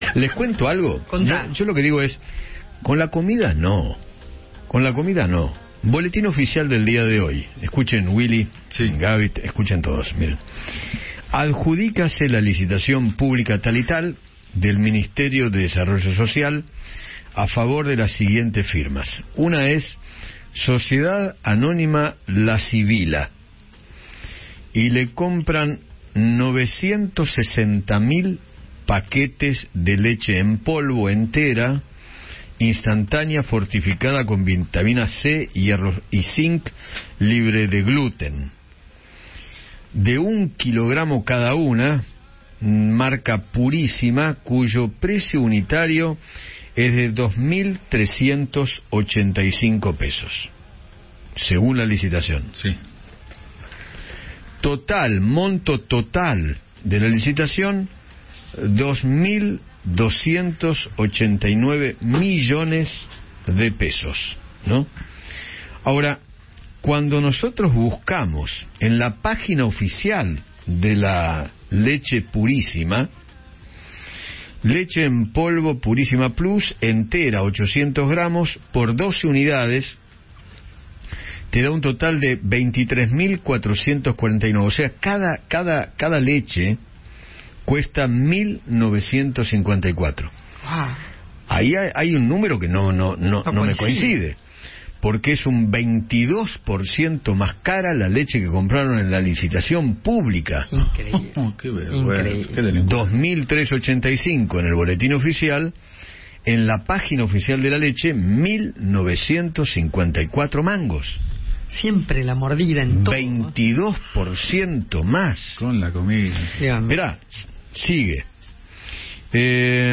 El Gobierno cerró la licitación de leche con precios exorbitantes y Eduardo Feinmann explotó: “¡Con la comida no!” - Eduardo Feinmann
El conductor de Alguien Tiene que Decirlo se refirió a una publicación realizada en el Boletín Oficial donde se adjudica la compra de leche a distintas empresas.